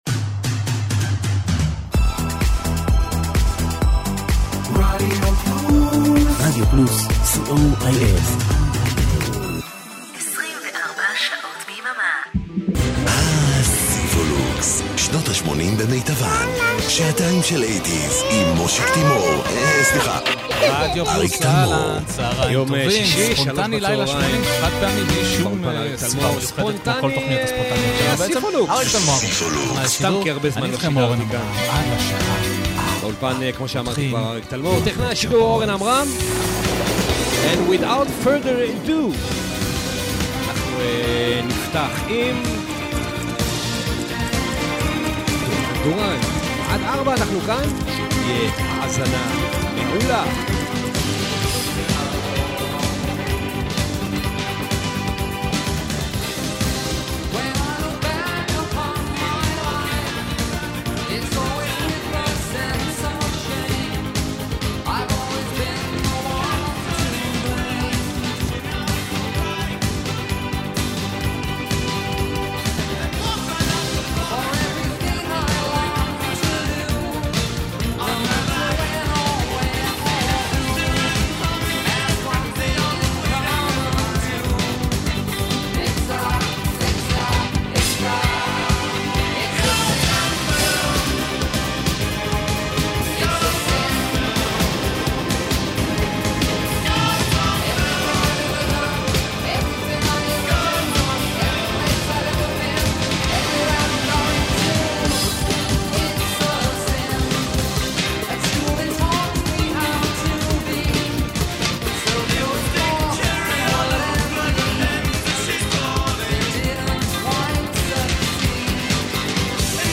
שמועות אומרות שבשידור החי של התכנית הזו מאזינים התלוננו על כאבי ראש עזים על סף המיגרנות.
למי שפיספס / למי שמעוניין להאזין לקקופוניה הזו שוב / למי שרוצה להאזין פעמיים, פעם לערוץ הימני ופעם לשמאלי.